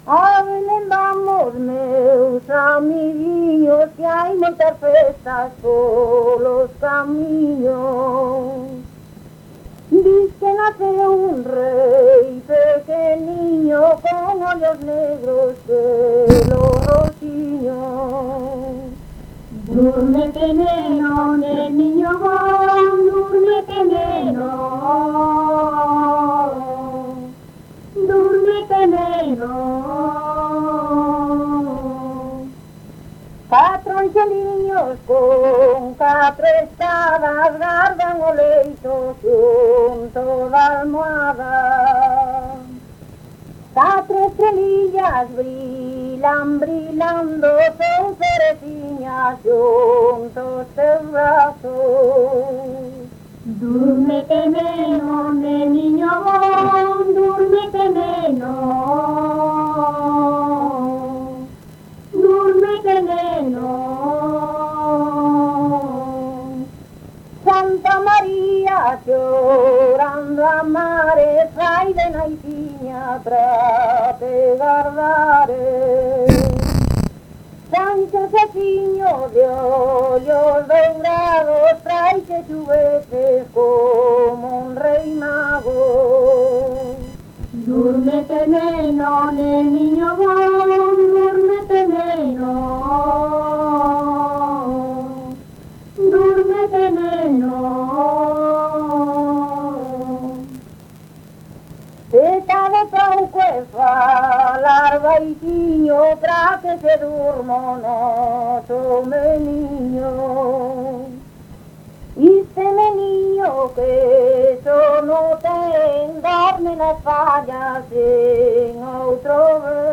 Palabras chave: nadal arrolo
Tipo de rexistro: Musical
Lugar de compilación: Chantada - A Grade (San Vicente) - Quintá
Soporte orixinal: Casete
Instrumentación: Voz
Instrumentos: Voz feminina